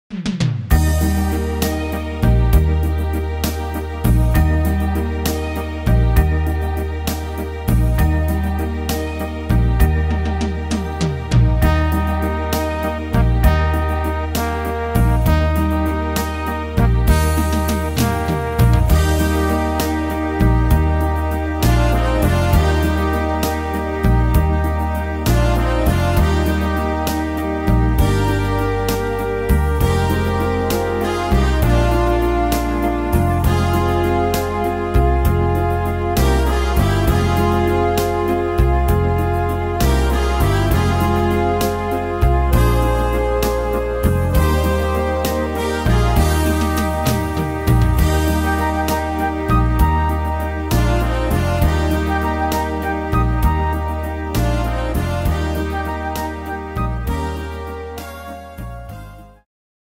Tempo: 66 / Tonart: Eb-Dur